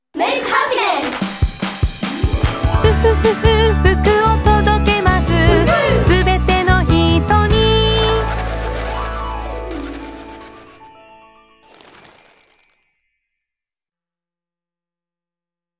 フフフフ〜♫と軽やかなメロディを口ずさめば、自然と朗らかな気持ちになります。